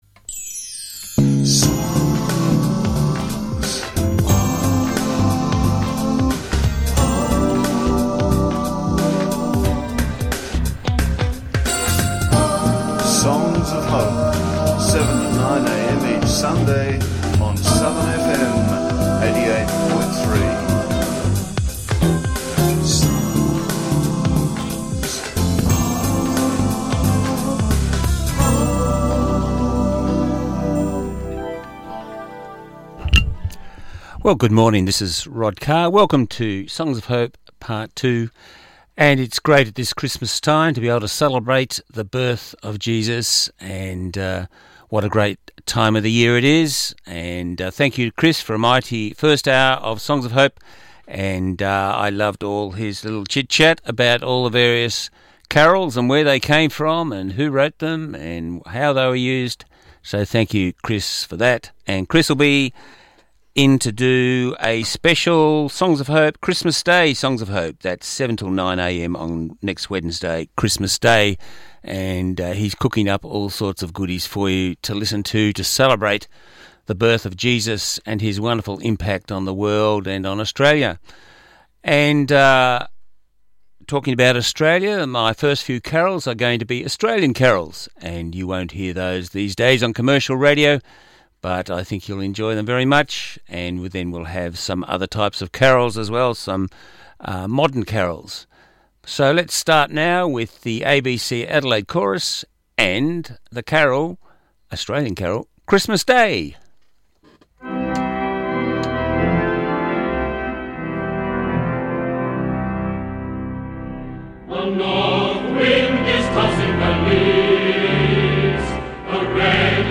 Christmas Carols
This is a recording of Songs of Hope Australian carols broadcast on 22Dec19.